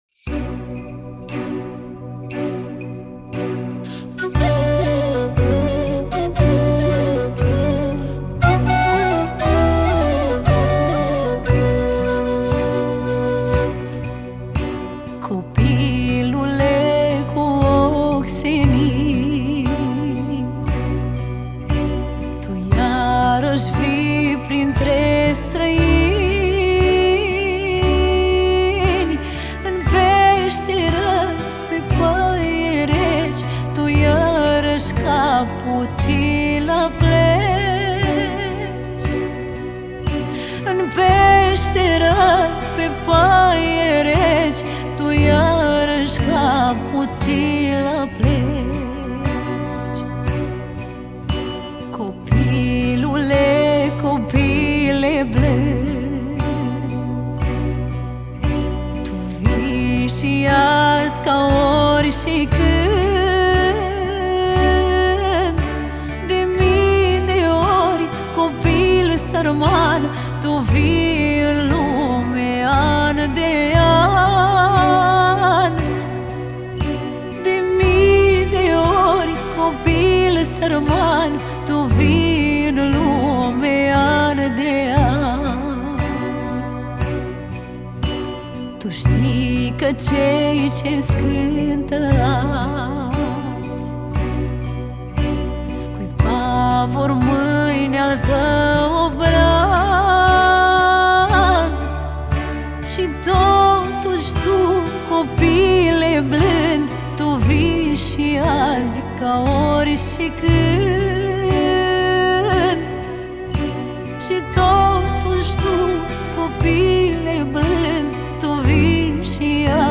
o piesă care îmbină emoție și ritm
Colinde de Craciun